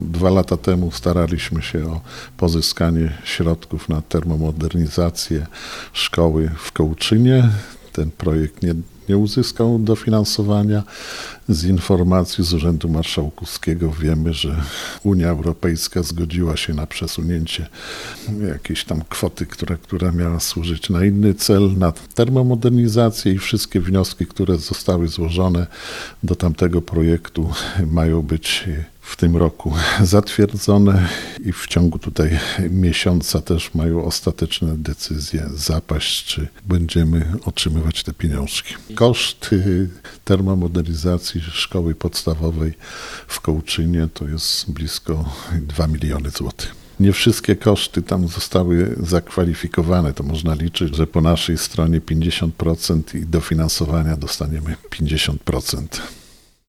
Satysfakcji nie kryje wójt gminy Krzeszyce Stanisław Peczkajtis: